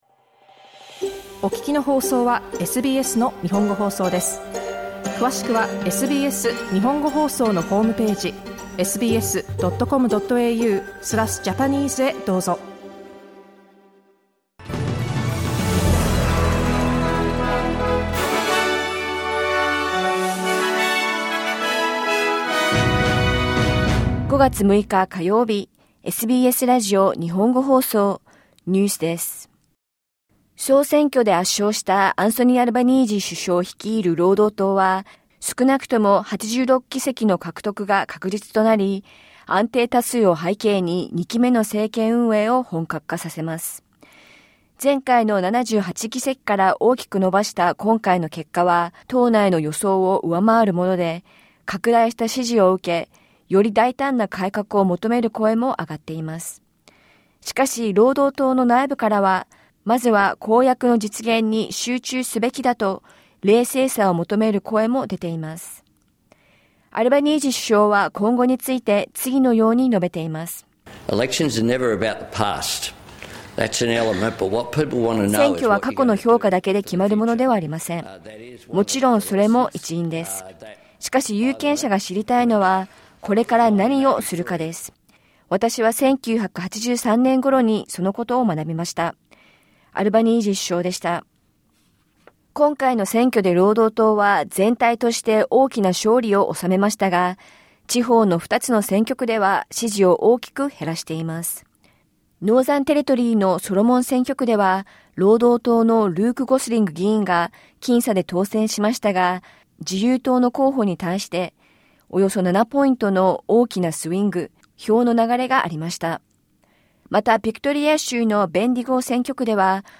トランプ大統領が、国外で制作された映画に対して100%の関税を課すと発表したことを受け、オーストラリア政府は、自国の映画産業を守る姿勢を示しています。イスラエル政府が、パレスチナ自治区ガザでの軍事作戦をさらに拡大する計画を承認しました。午後１時から放送されたラジオ番組のニュース部分をお届けします。